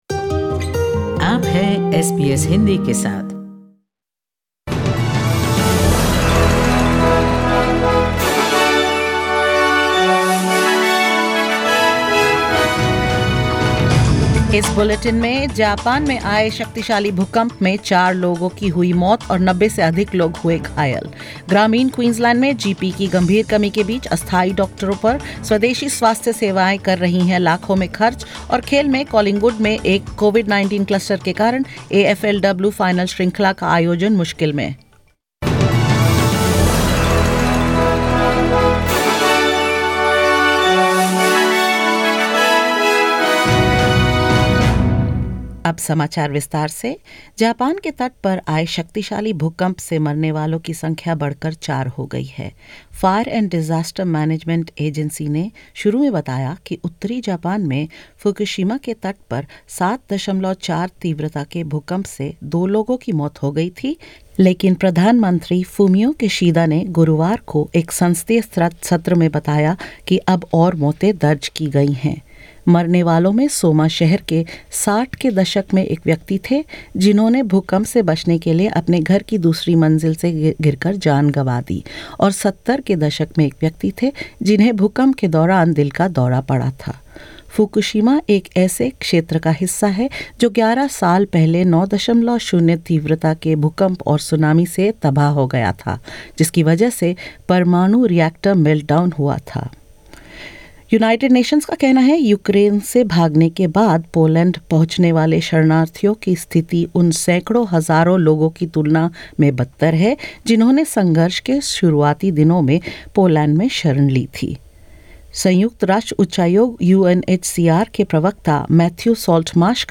In this latest SBS Hindi bulletin: The United Nations says newly arriving Ukrainian refugees in Poland are more traumatised than those who came earlier; Indigenous health services spend millions on temporary doctors due to GP shortage in rural Queensland; And in the AFLW final series, COVID-19 cluster at Collingwood causes the Magpies' qualifying final to be postponed and more.